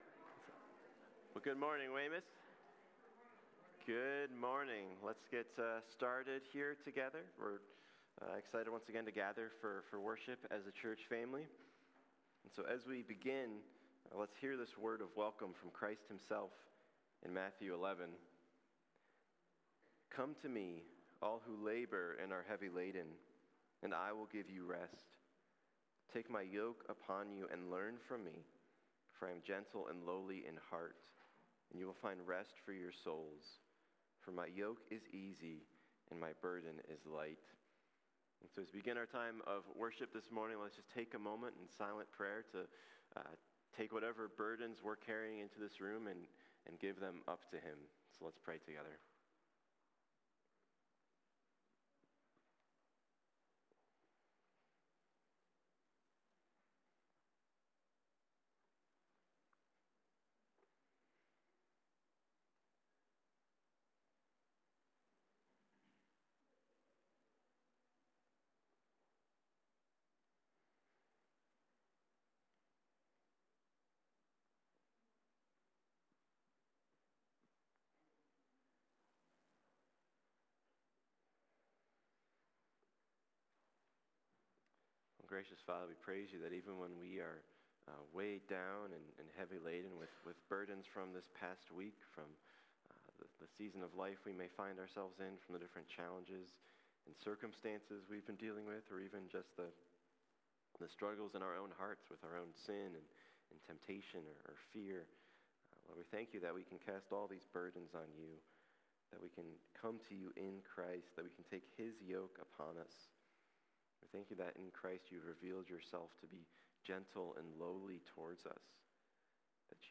1 PETER 3:1-7 Service Type: Sunday Morning Serve one another in submission to God.